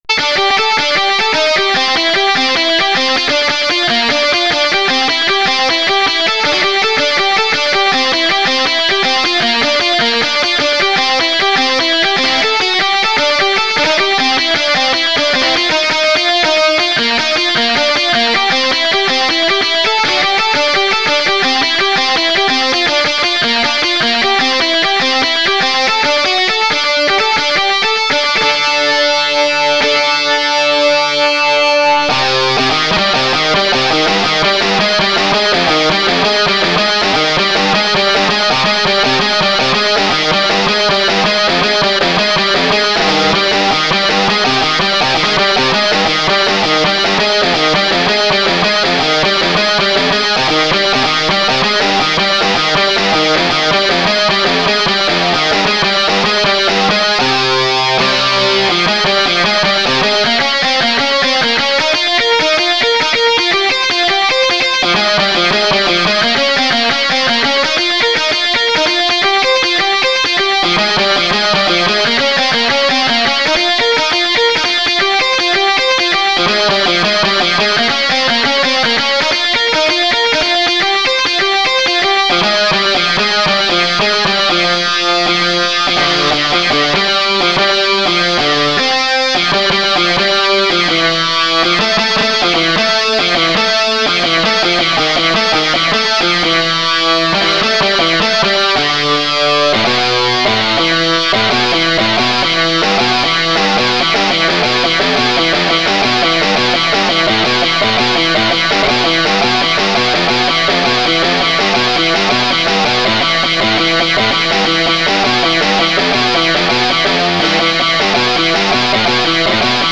horror punk rock